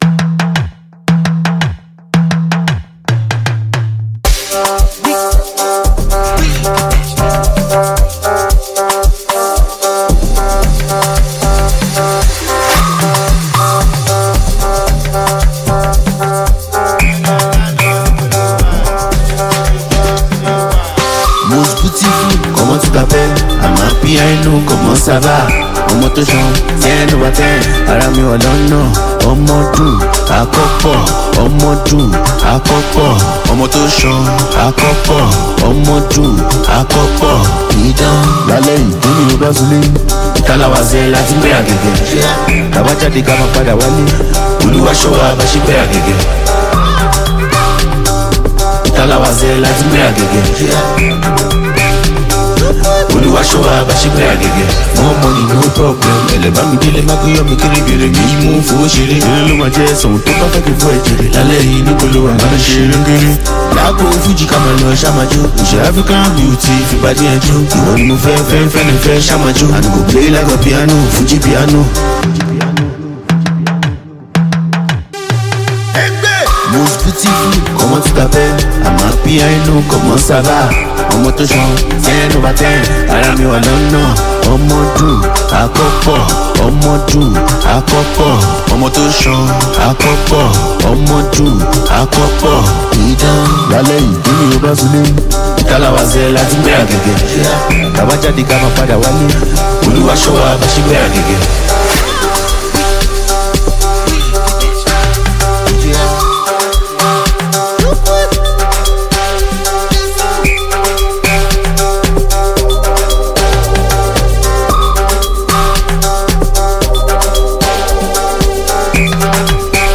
Nigerian singer-songwriter and performer
With its infectious melodies and captivating lyrics